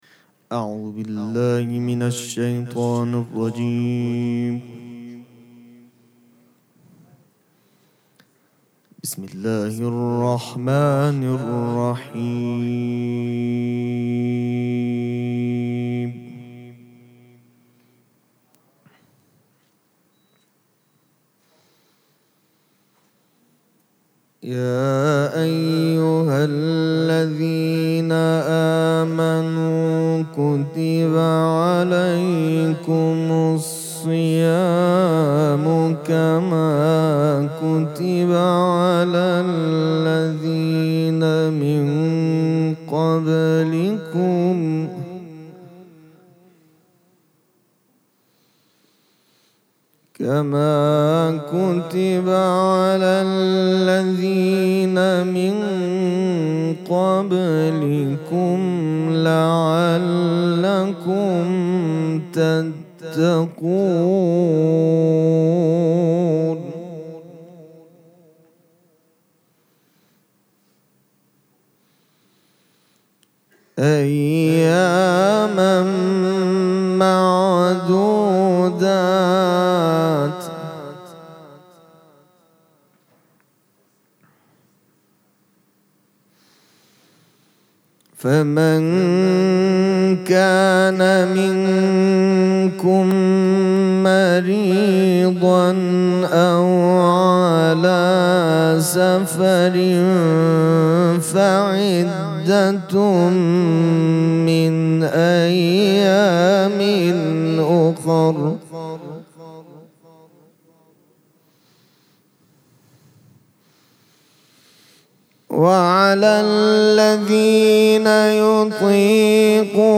قرائت قرآن کریم
مراسم مناجات شب دوم ماه مبارک رمضان
قرائت قرآن